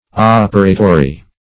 Operatory \Op"er*a*to*ry\, n.